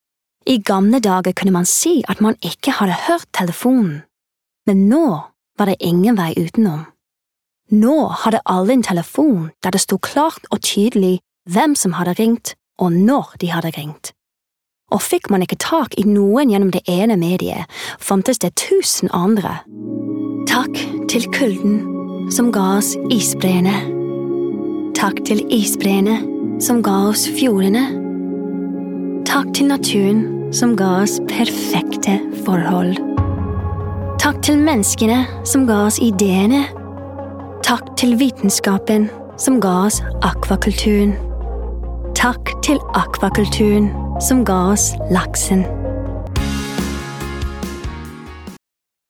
Norwegian Language Showreel
Female
Bright
Youthful